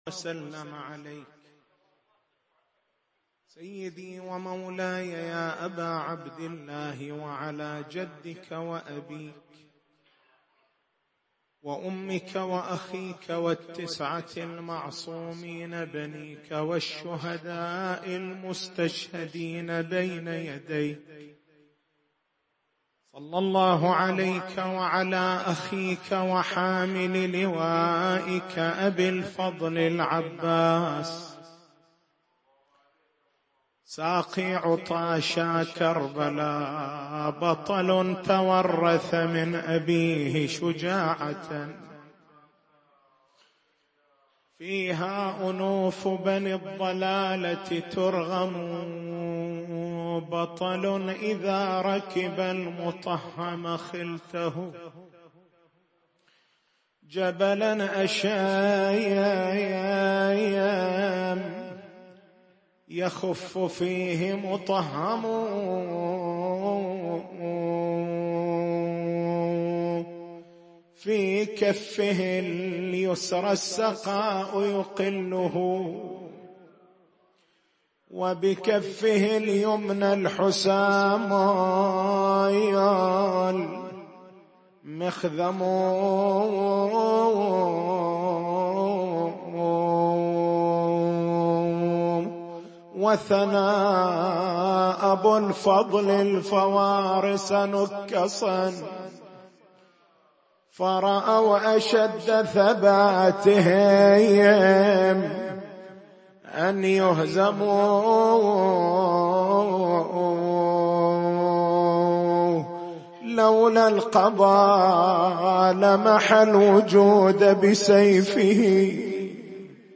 تاريخ المحاضرة: 21/01/1439 نقاط البحث: هل يُتَصَوَّر في حقّ العبّاس (ع) أن ينسى عطش الحسين (ع)؟ لماذا لم يشرب العباس (ع) من الماء، مع أنّ شربه كان سيعينه على نصرة سيّد الشهداء (ع)؟